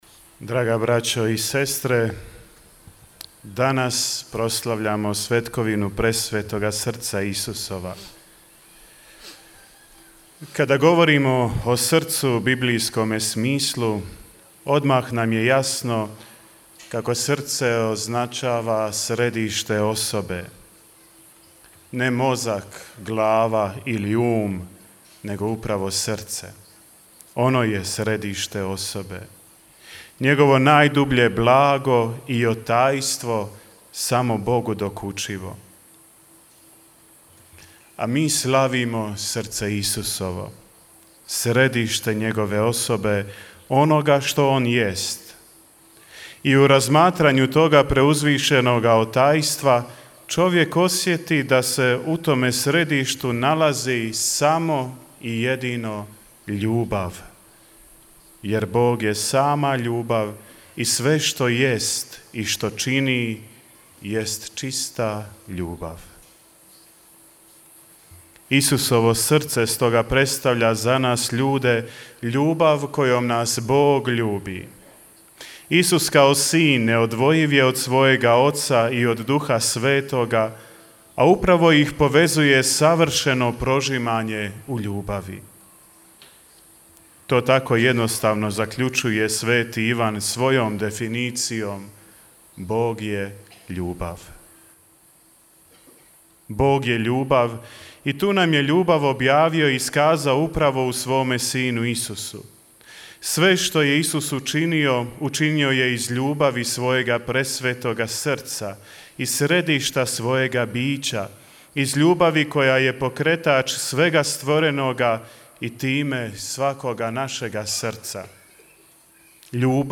Homilija
misno slavlje u na vanjskom oltaru crkve sv. Jakova u Međugorju